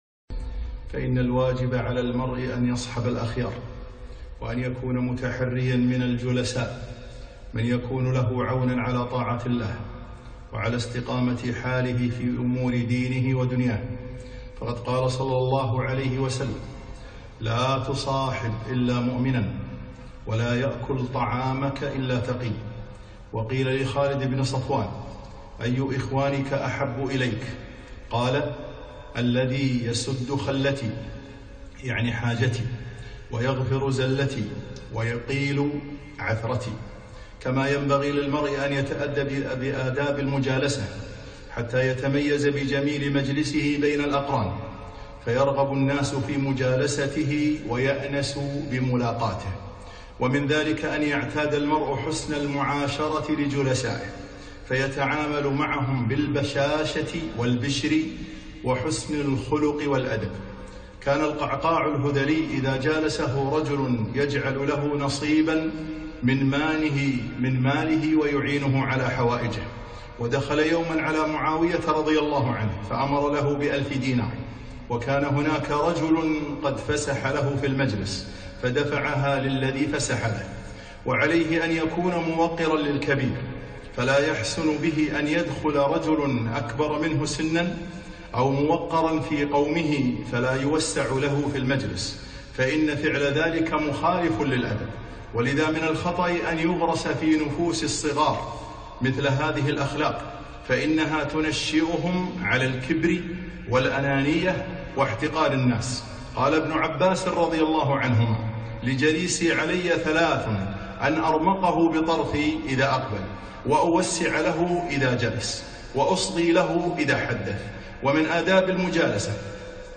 خطبة - أدب المجالسة